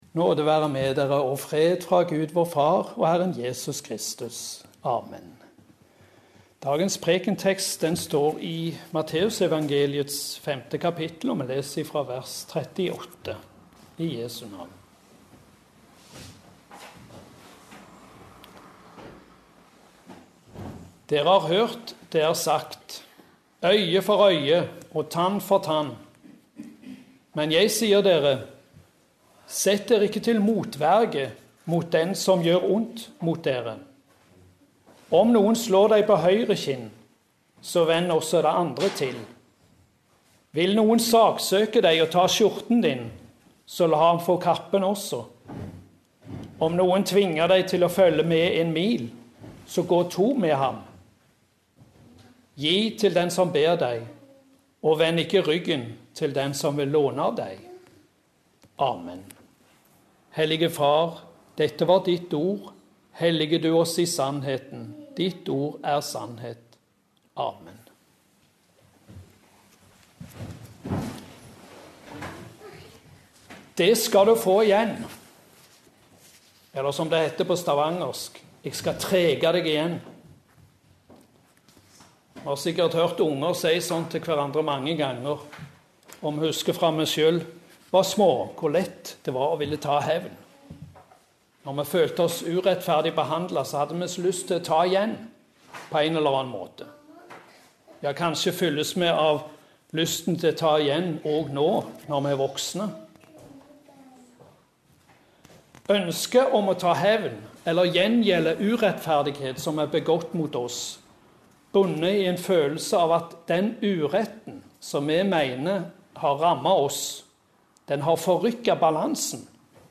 Preken på 4. søndag etter Treenighetsdag